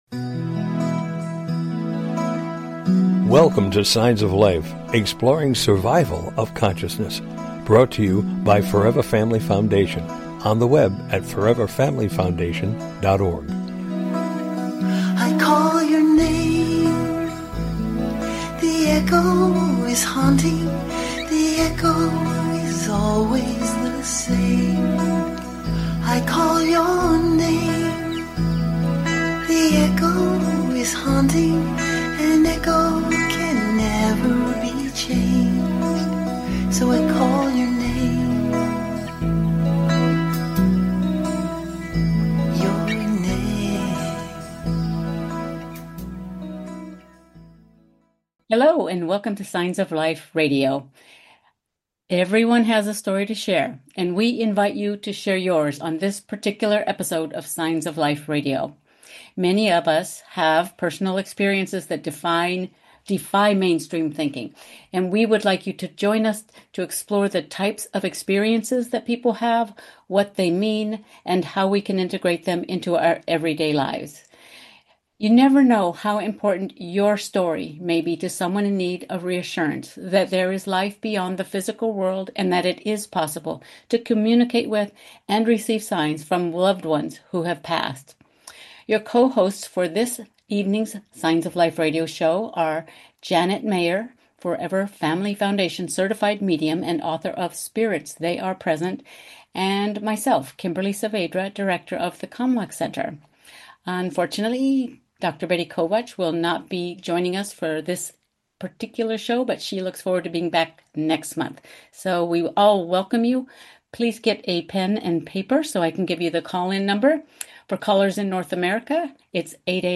Talk Show Episode, Audio Podcast, Signs of Life - Personal Experiences